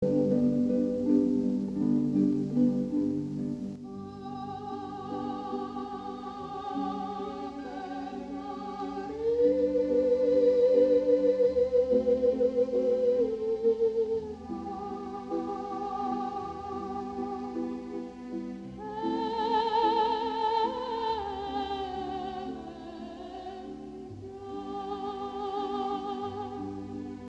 Arranging Schubert "Ave Maria" for Carillon Solo and Duet
Vocal melody is already in the middle layer. Harp-like accompaniment in 3rds is in higher octaves. Very simple bass line.
Took grace notes out of melody.
Kept the 3rd at the first beat of second triplet to keep rhythm moving.